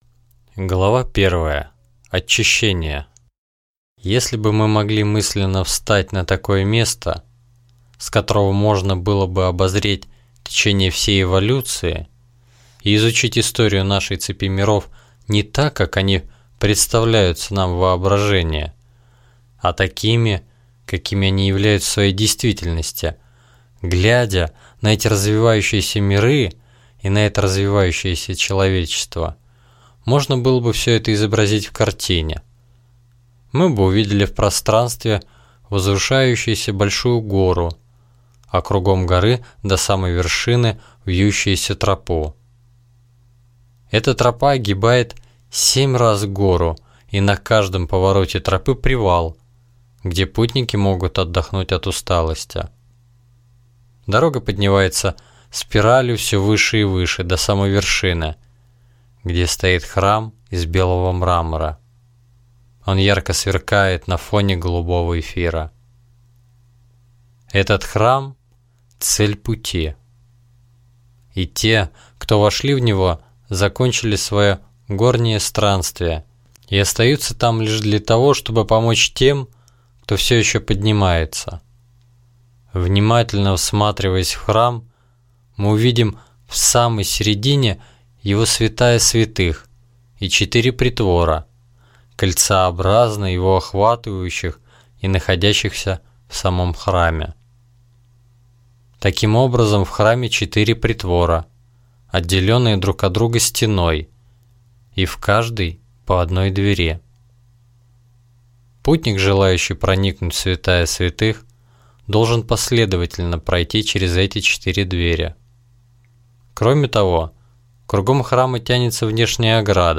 Аудиокнига В преддверии Храма | Библиотека аудиокниг